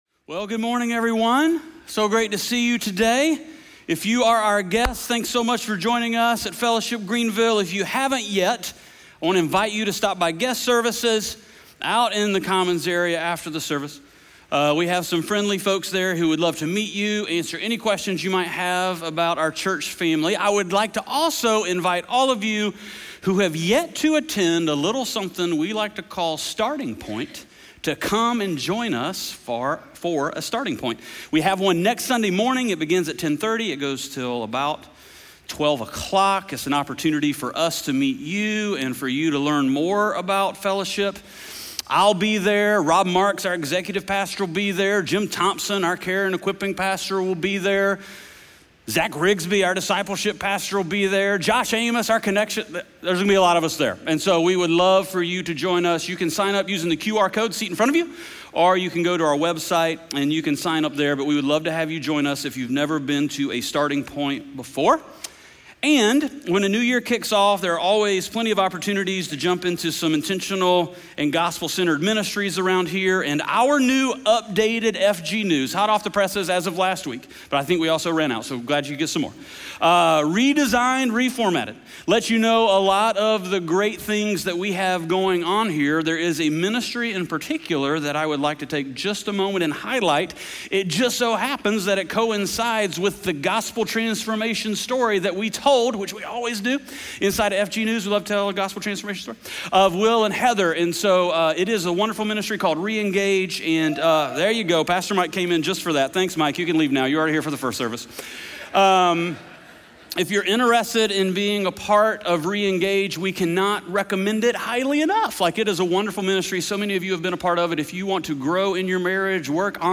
SERMON SCREENSHOTS & KEY POINTS